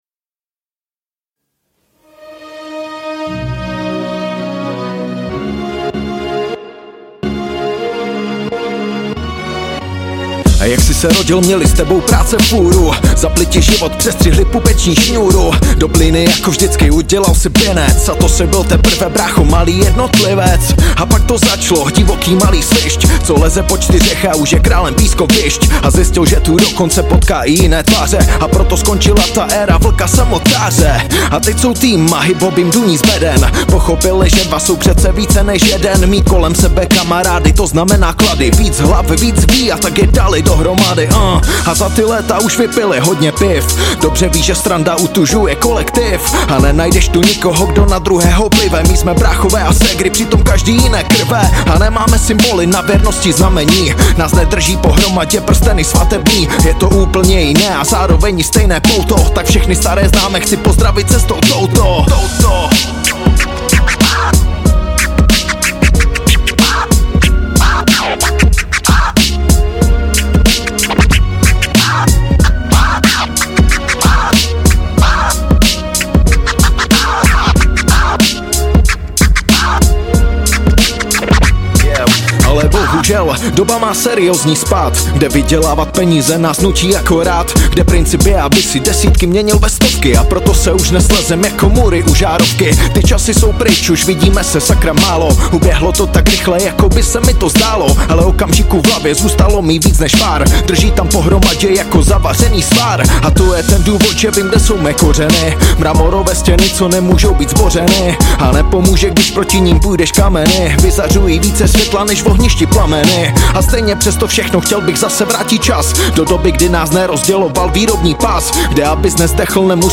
Žánr: Hip Hop/R&B